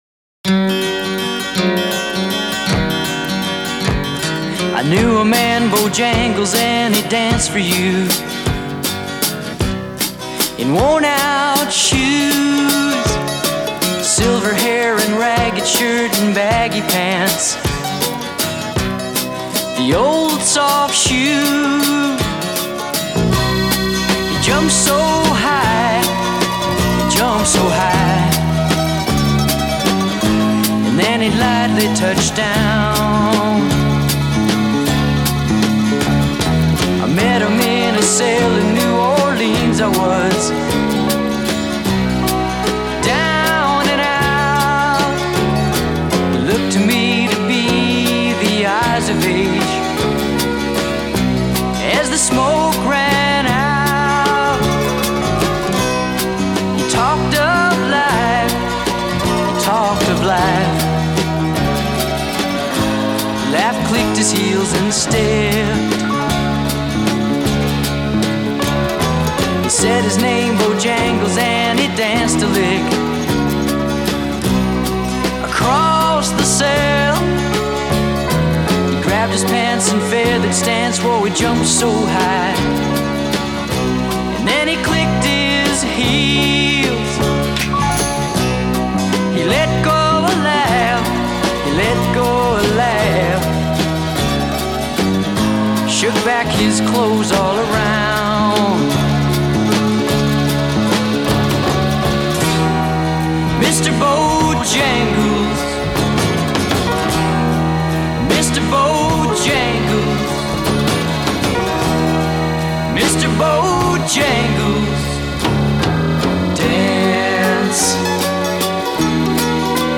accordion and mandolin